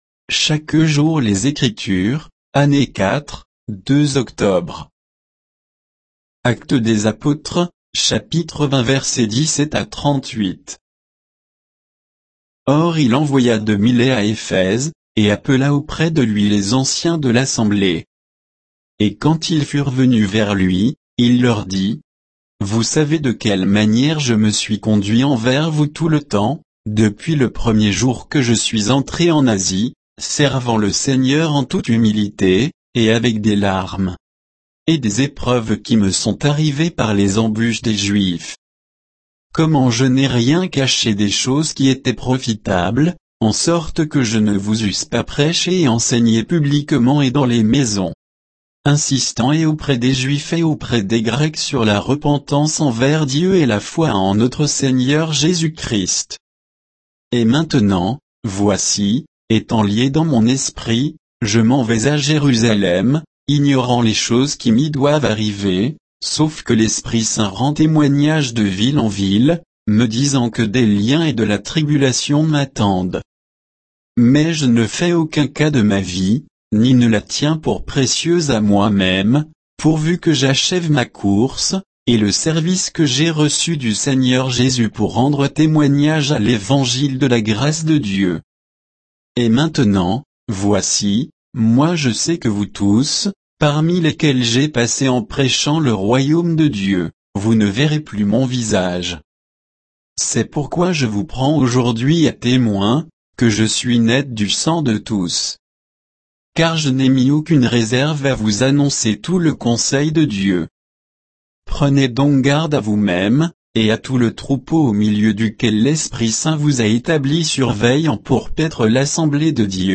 Méditation quoditienne de Chaque jour les Écritures sur Actes 20, 17 à 38